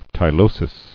[ty·lo·sis]